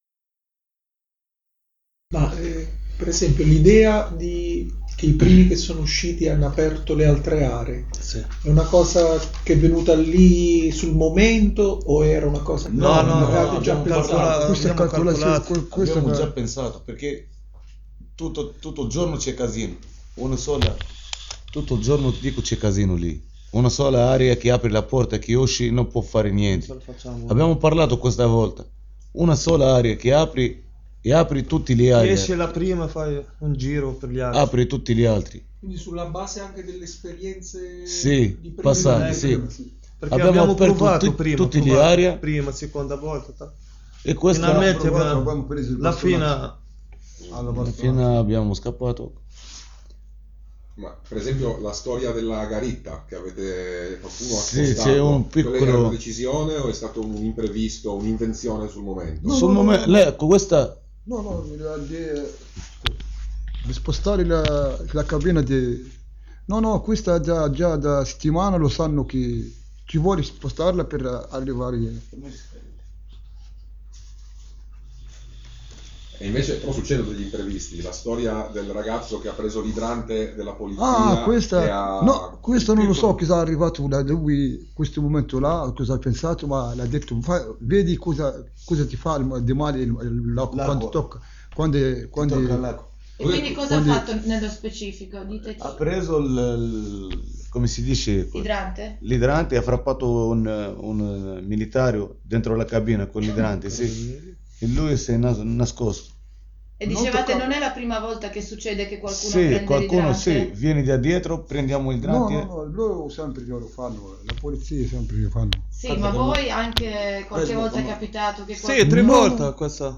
Intervista con gli evasi
Questa mattina su Radio Blackout 105.250FM, durante la trasmissione Macerie (su Macerie) in onda tutti i giovedì dalle 10.30 alle 12.30, sono state mandate in onda due interviste a tre evasi dal Cie di Torino a Natale, e ad un altro evaso a Capodanno.
Ascolta la seconda parte dell’intervista con tre reclusi evasi a Natale (9 min)